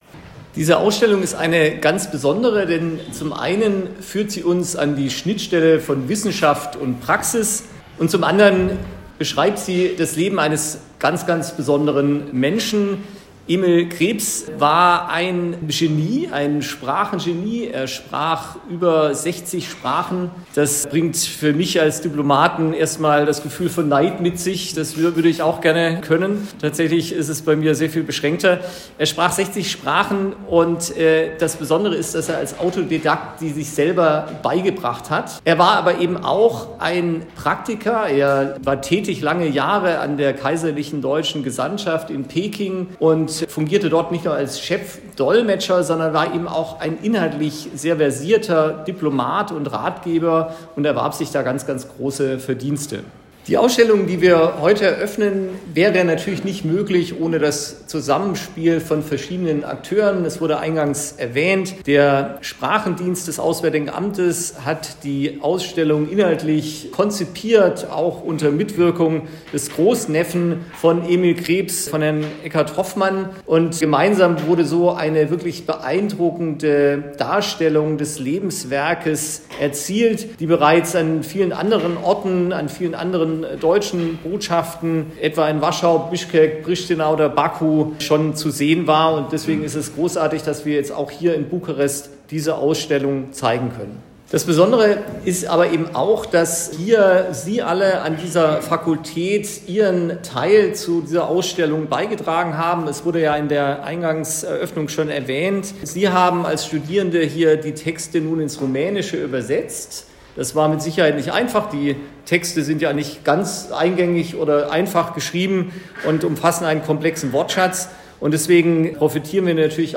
Nachstehend der Bericht des rumänischen Rundfunks mit der Begrüßungsrede des Botschafters, Herrn Dr. Peer Gebauer.
Nachstehend berichtet der rumänische Rundfunk über die Ausstellungseröffnung.